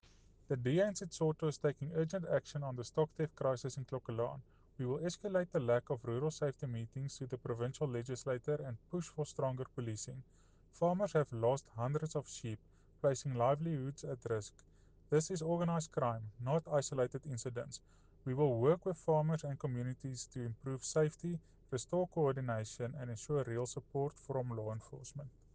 Afrikaans soundbites by Cllr Jose Coetzee and